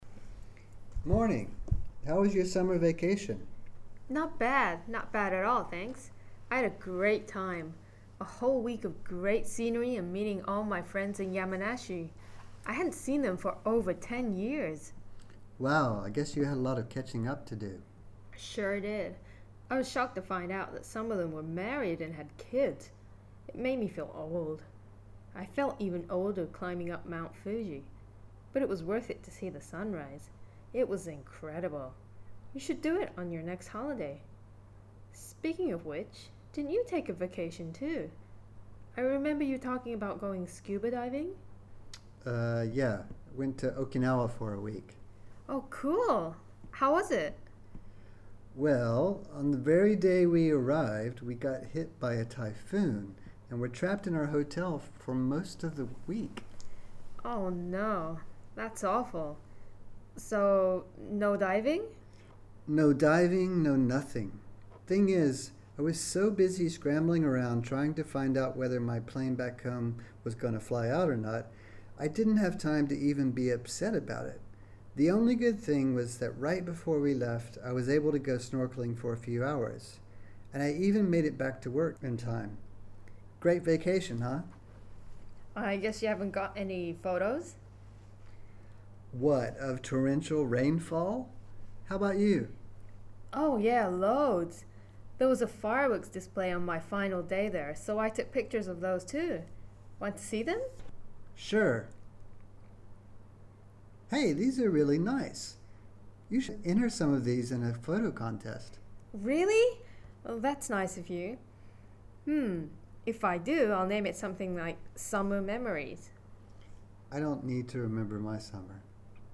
全部の音声(普通）